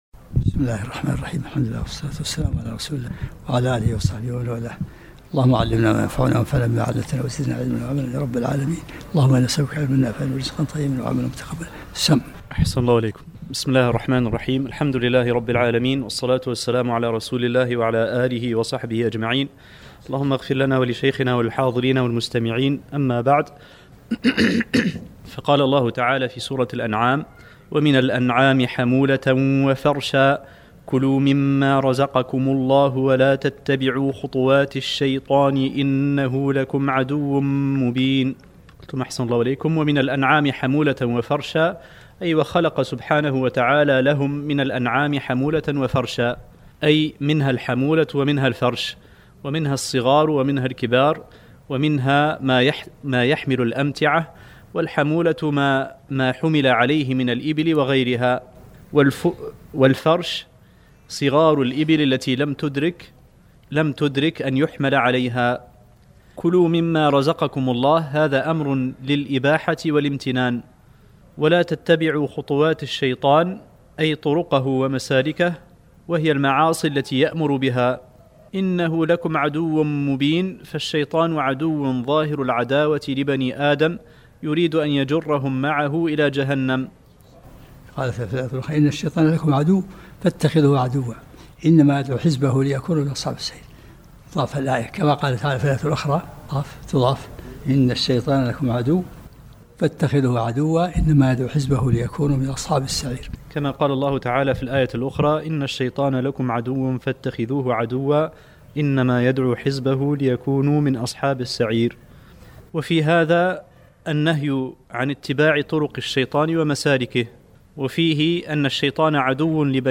الدرس الثامن و العشرون من سورة الانعام